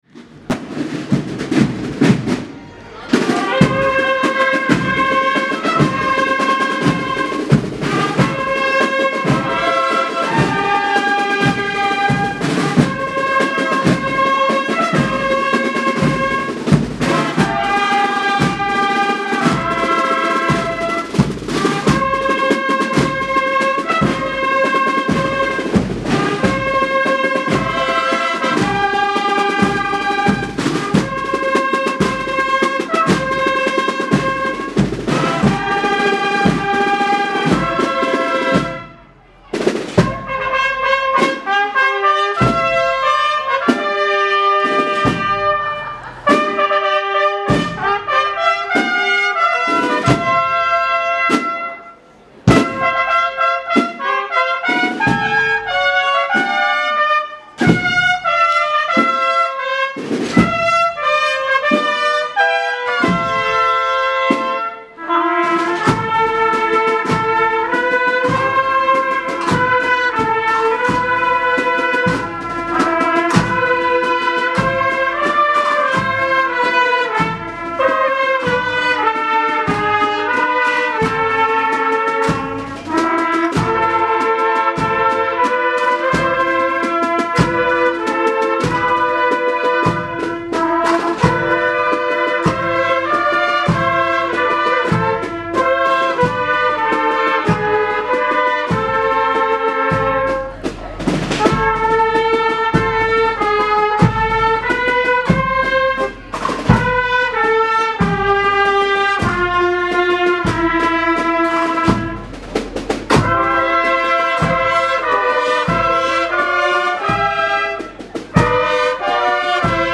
Procesión San Roque 2015
Posteriormente tuvo lugar la procesión con la imagen de San Roque que recorrió las calles del barrio, para finalizar en la Ermita y que contó con la asistencia de vecinos, autoridades y la banda de cornetas y tambores de la Hermandad de Jesús en el Calvario y Santa Cena.